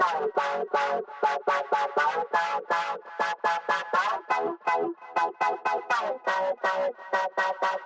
广场合成器
Tag: 128 bpm House Loops Synth Loops 1.26 MB wav Key : C